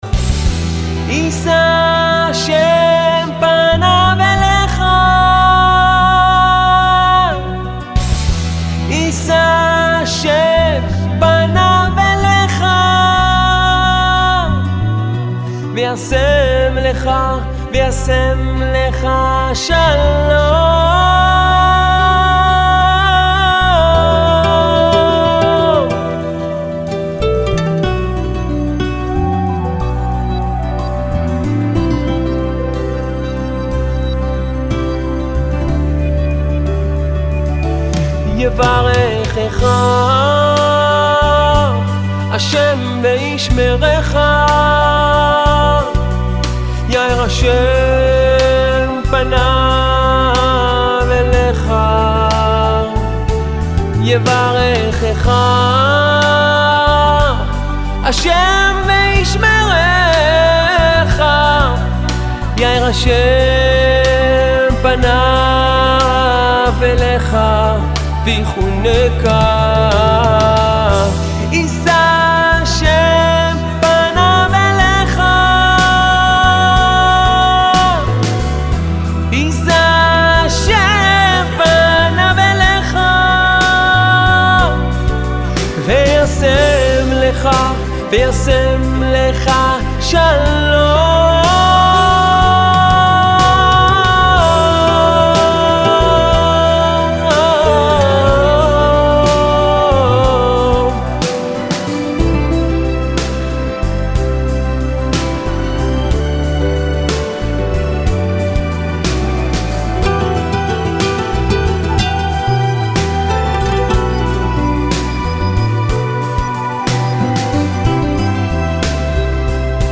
בשילוב שופרות ומתופפים